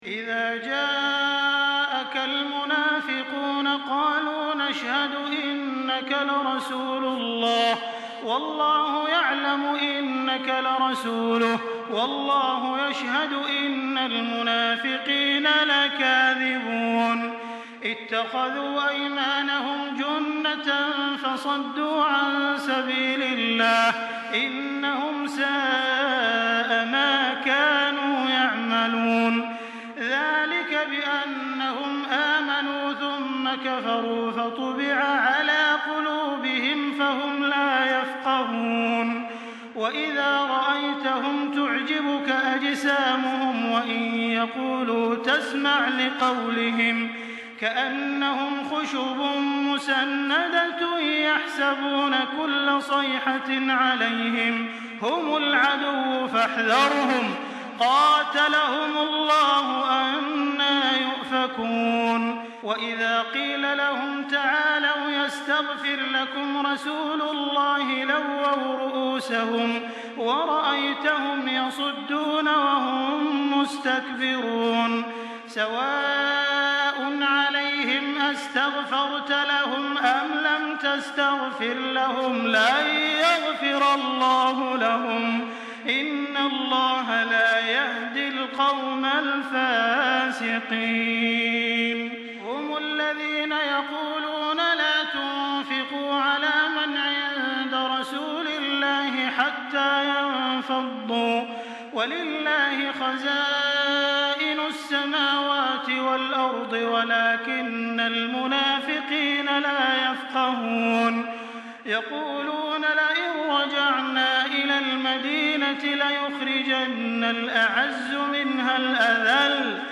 Surah Münafikun MP3 by Makkah Taraweeh 1424 in Hafs An Asim narration.
Murattal Hafs An Asim